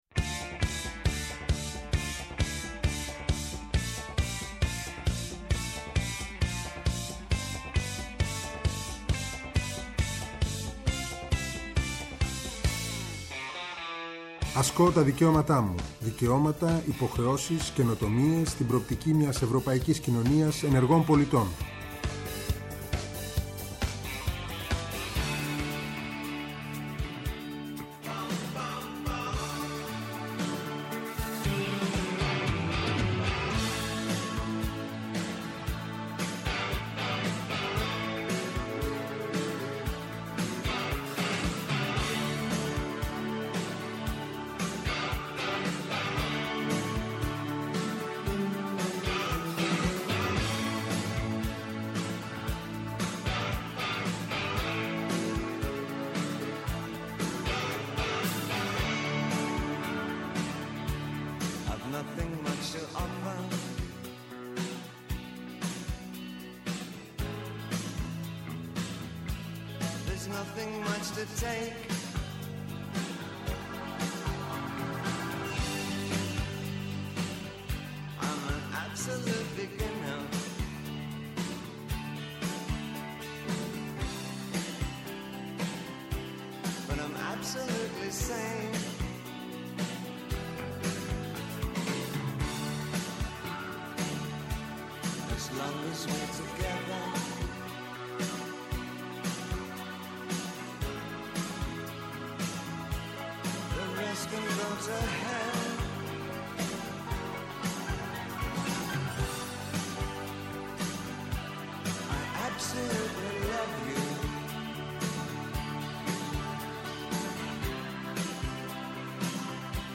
-Ο Δημήτρης Σκάλκος, Γενικός Γραμματέας Προγράμματος Δημοσίων Επενδύσεων και ΕΣΠΑ, μιλά για το ΕΣΠΑ 2021-2027 και την σημασία του μαζί με το Ταμείο Ανάκαμψης στην προοπτική του 2030